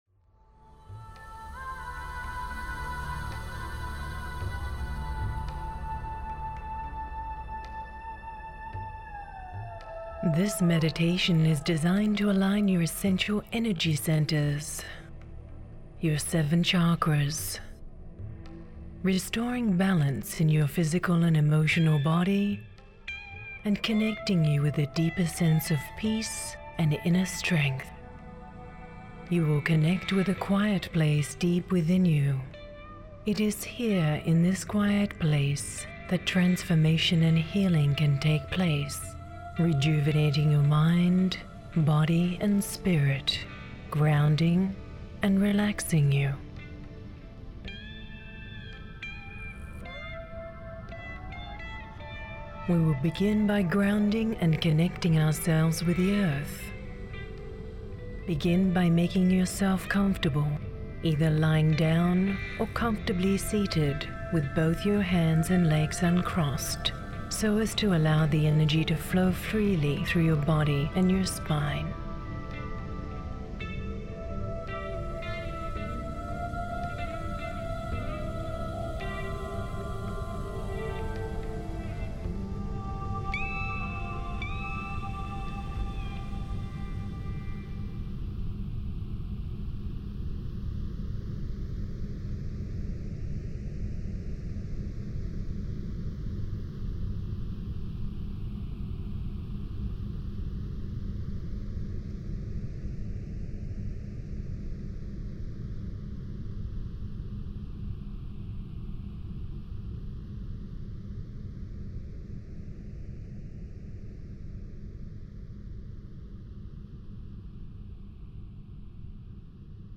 Dolphin sounds and music combine to help align and balance each of the seven major chakras in this verbally guided Hemi-Sync® exercise